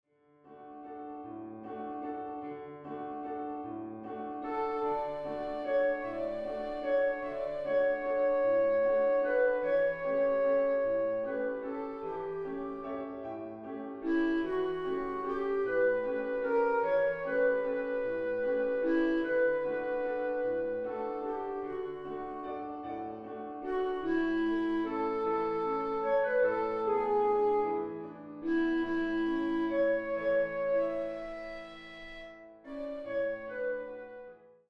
Duett mit Mezzostimme zum einstudieren der Mezzolinie
Klaviersound